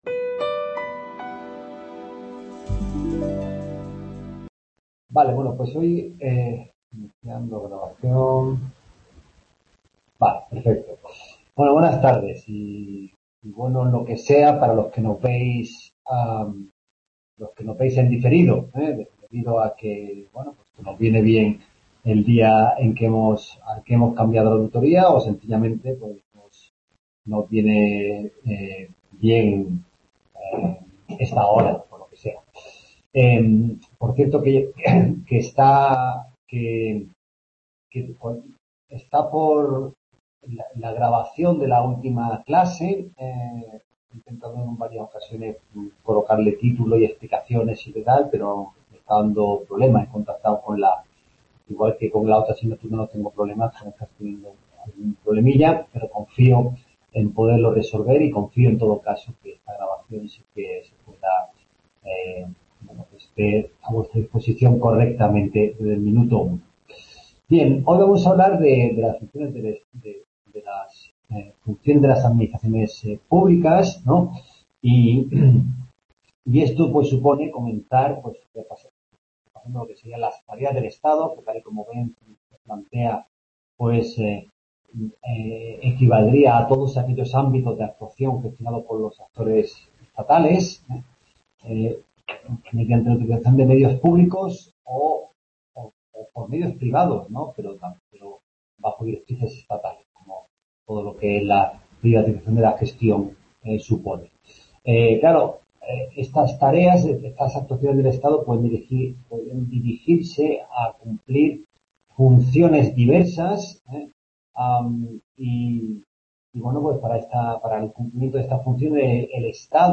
En esta tutoría se expone el tema segundo de la asignatura y se responde a preguntas de los estudiantes al respecto.